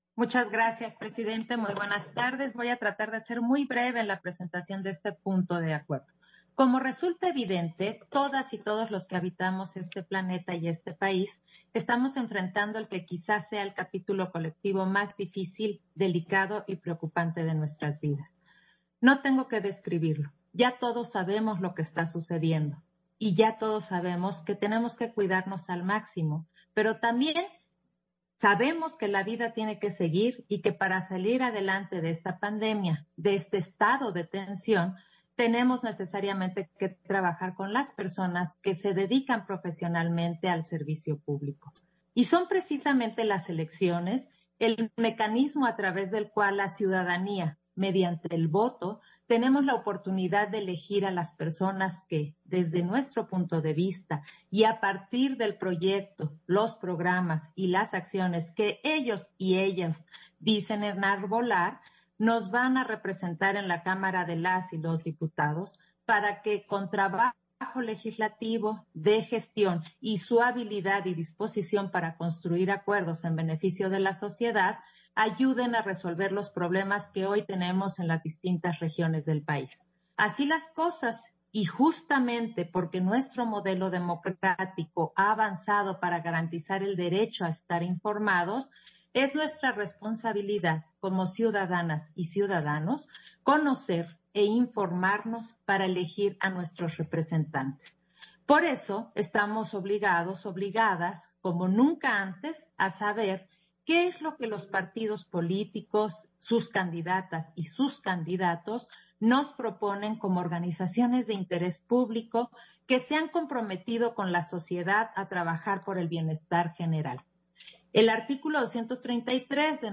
Intervención de Claudia Zavala en Sesión Ordinaria, en el punto relativo a las solicitudes de registro a las plataformas electorales presentadas por los partidos políticos